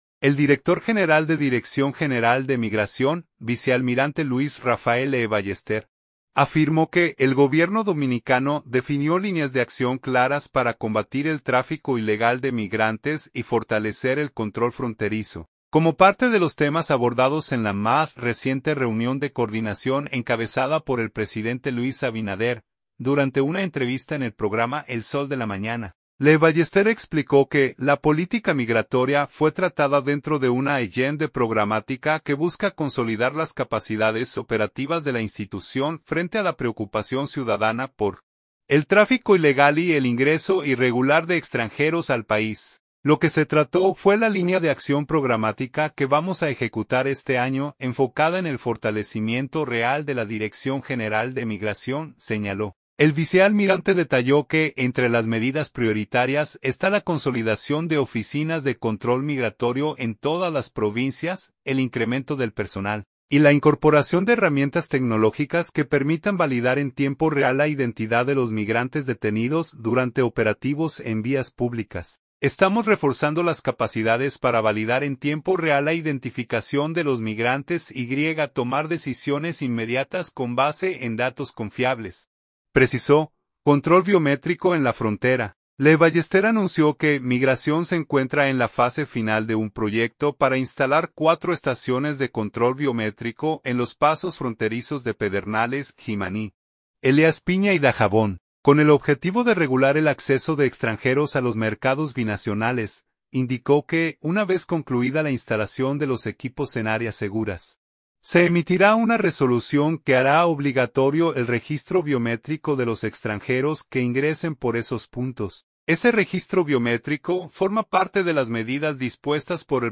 Durante una entrevista en el programa El Sol de la Mañana, Lee Ballester explicó que la política migratoria fue tratada dentro de una agenda programática que busca consolidar las capacidades operativas de la institución frente a la preocupación ciudadana por el tráfico ilegal y el ingreso irregular de extranjeros al país.